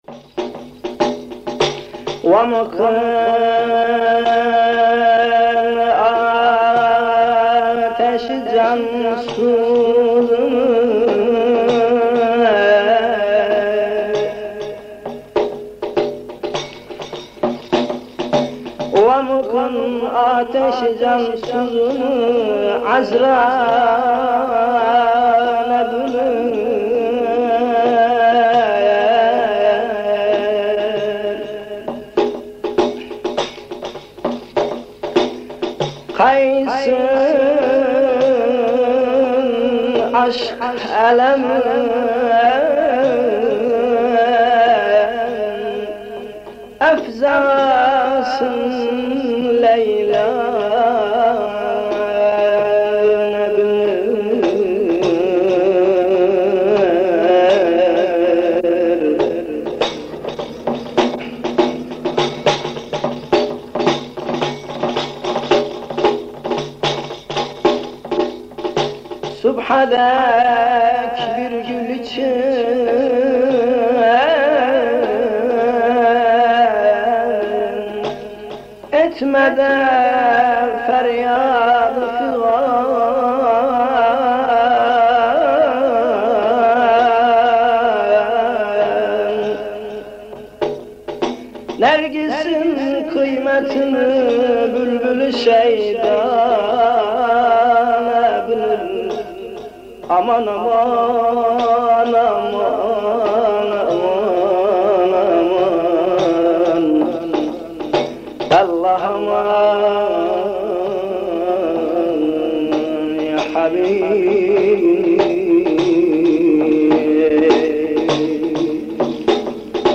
Etiketler: şanlıurfa, Tasavvuf